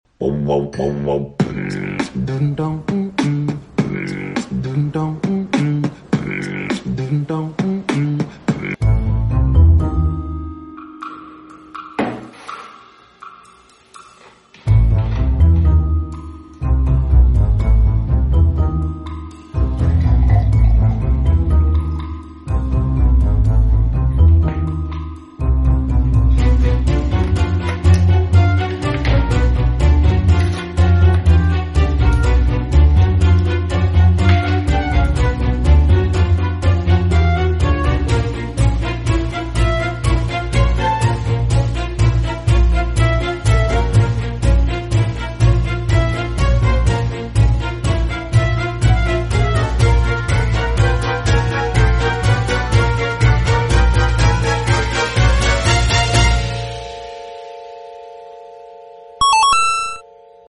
Battery powered hurricane sound effects free download